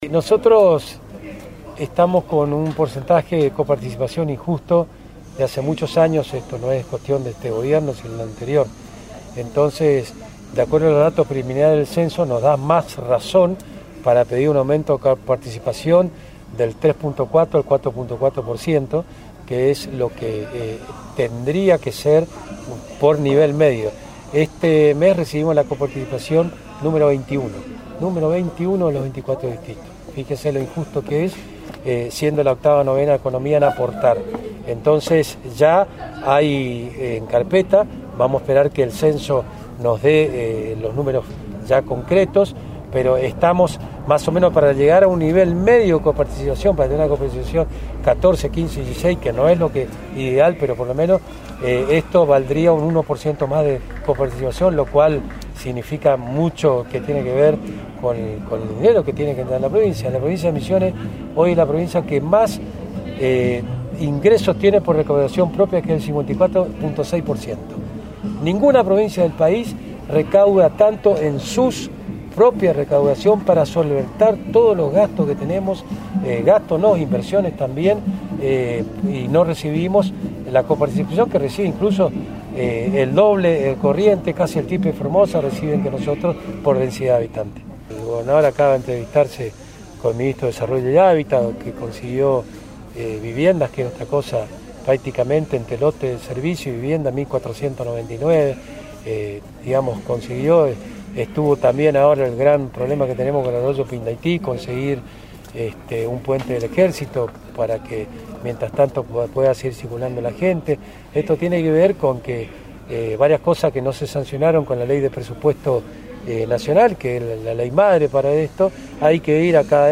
Audio: Vicegobernador Carlos Arce